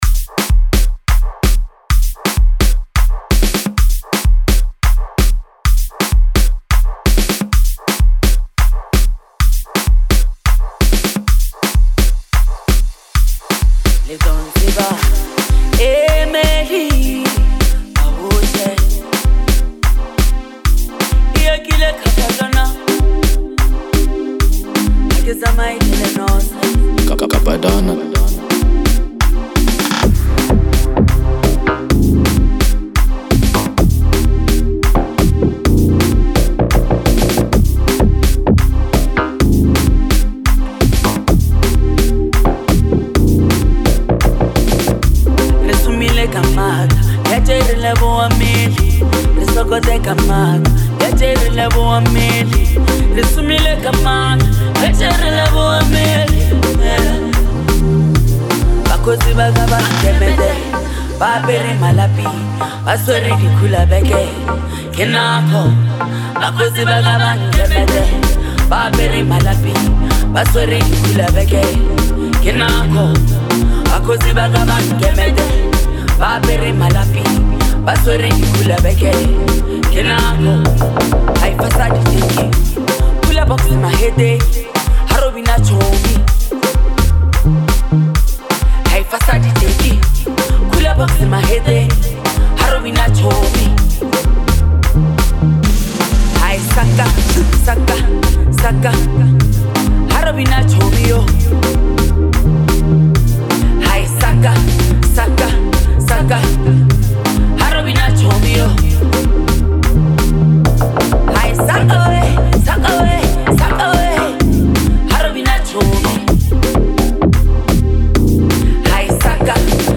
Lekompo
high-energy Lekompo anthem